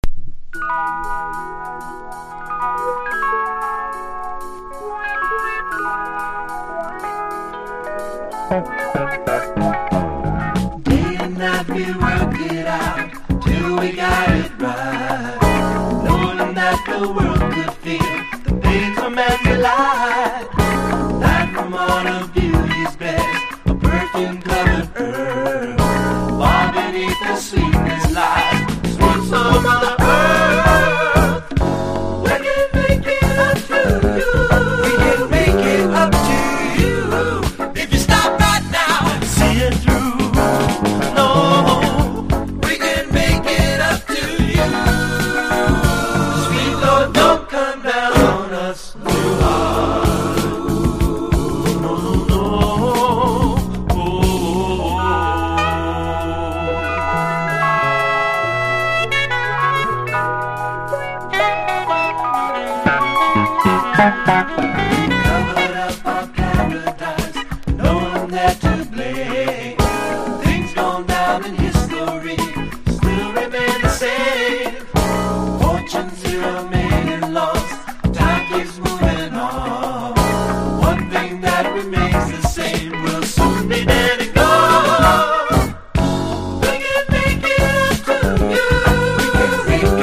ハワイ産メロウ・グルーヴの代表格